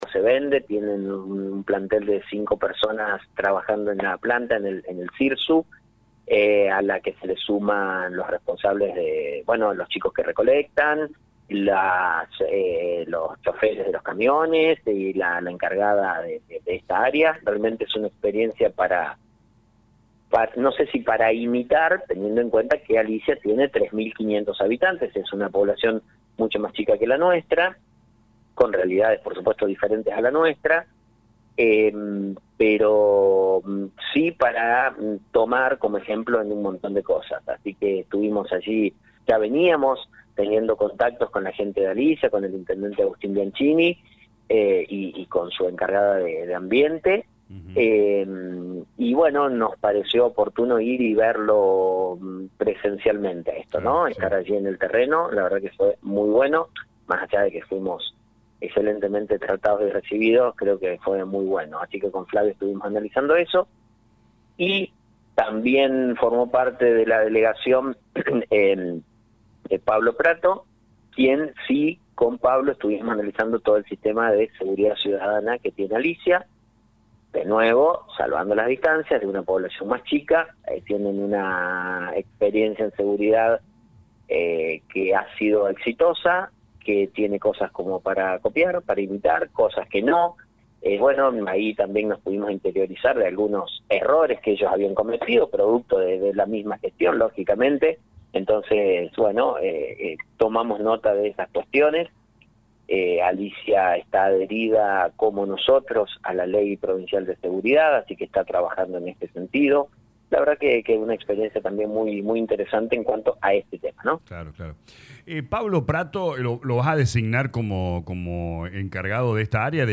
La baja en actividad económica también repercute en el municipio. Consultado al intendente Actis, señaló una baja en la recaudación de impuestos.